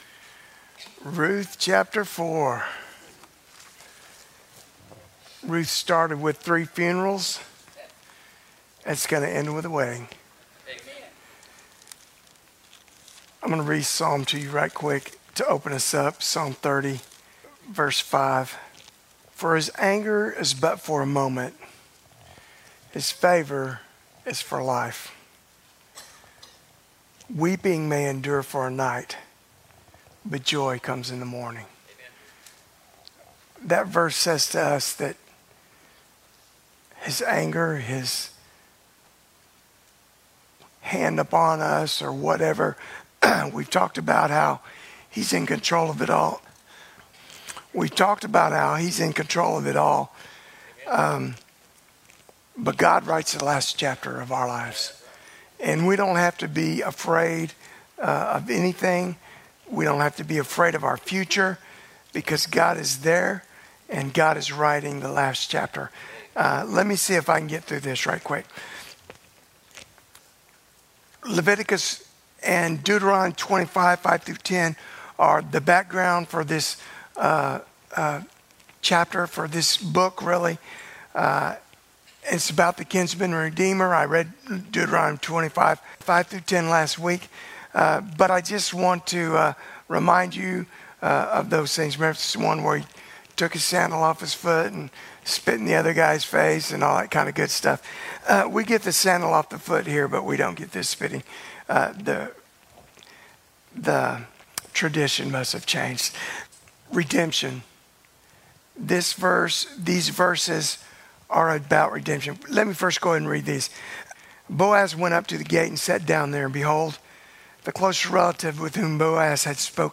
Sermons 2025
Sermons 2025 - First Baptist Church Bridge City, Texas